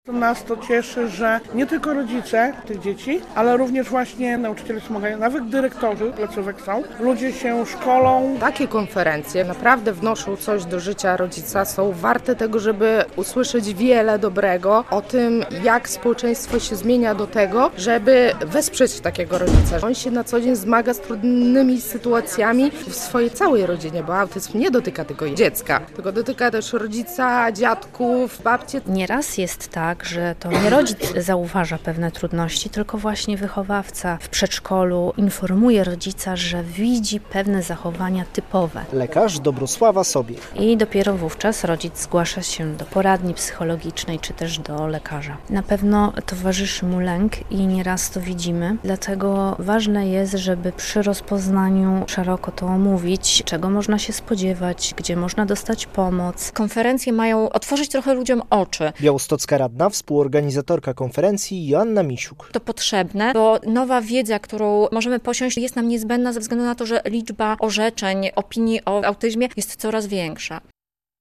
Światowy Dzień Autyzmu w VII LO w Białymstoku - relacja